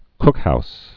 (kkhous)